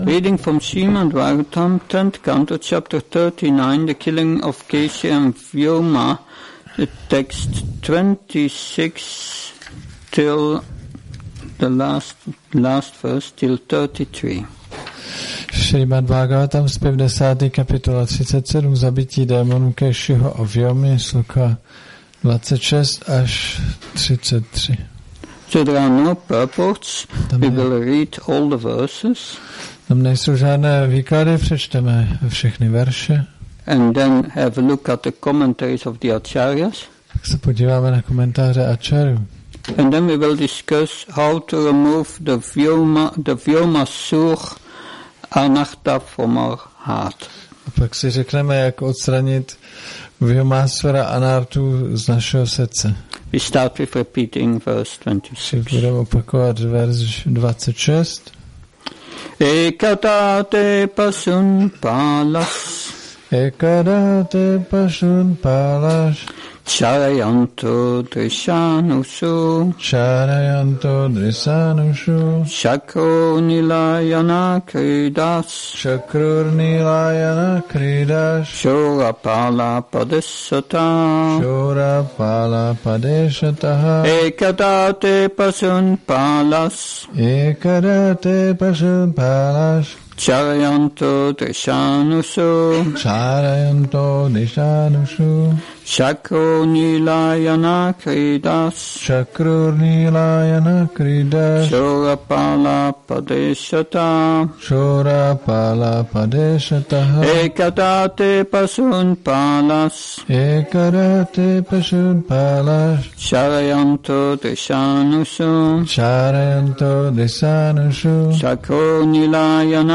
Šrí Šrí Nitái Navadvípačandra mandir
Přednáška SB-10.39.26